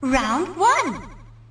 snd_boxing_round1.ogg